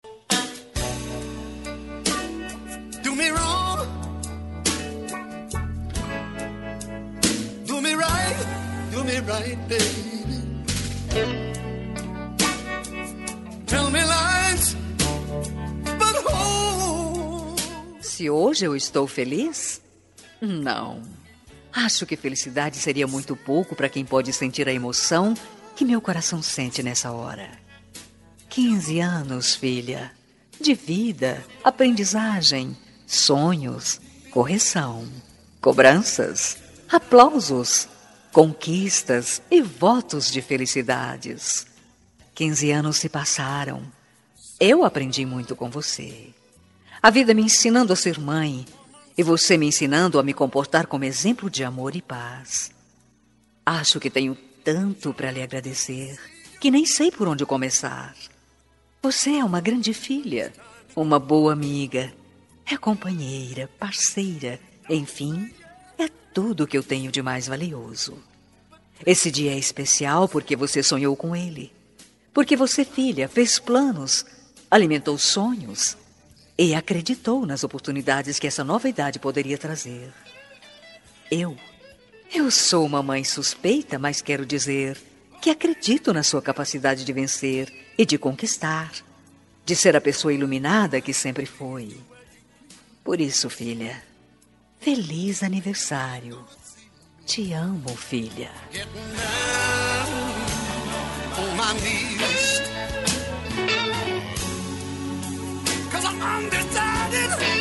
Aniversário de 15 anos – Voz Feminina – Cód: 33376 – Mãe